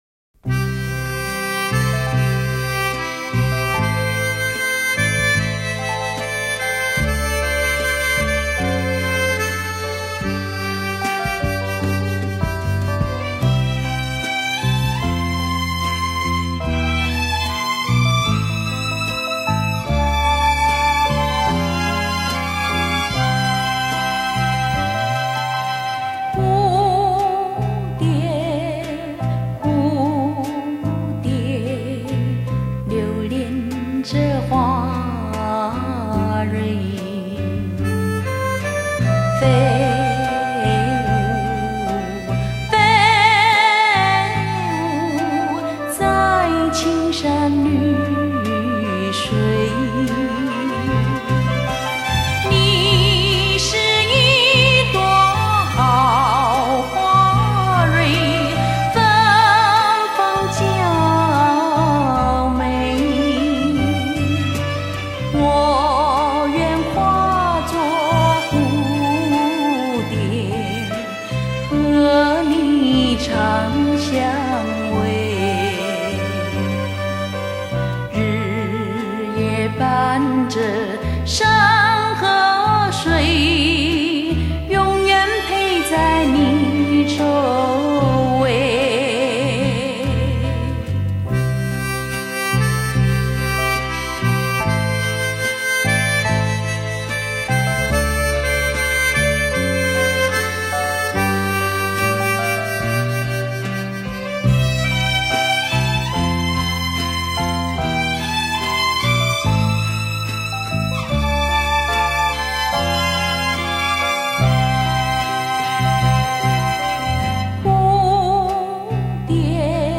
三厅文艺电影歌曲
原曲原唱电影原声带